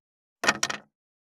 581肉切りナイフ,まな板の上,
効果音厨房/台所/レストラン/kitchen食器食材